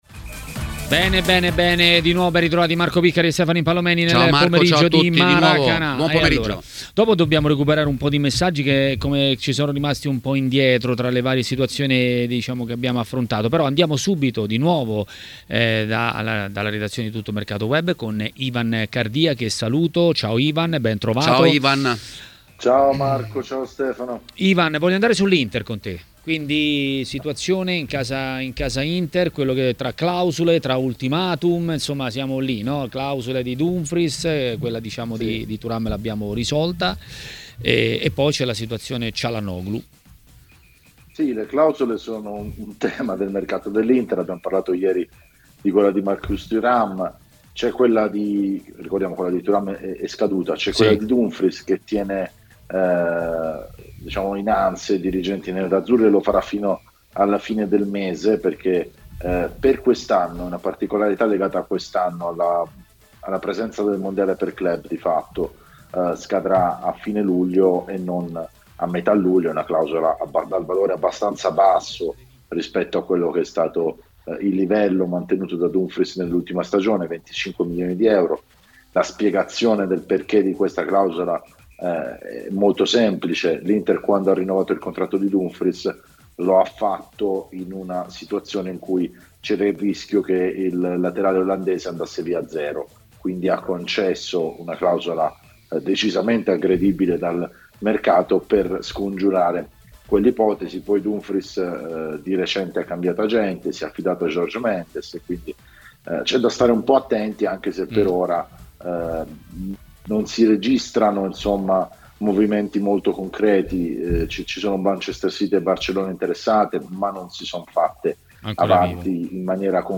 L'ex calciatore Roberto Tricella ha parlato a Maracanà, nel pomeriggio di TMW Radio.